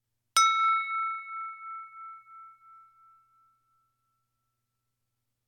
Wine glass 4
bell chime crystal ding wine-glass sound effect free sound royalty free Sound Effects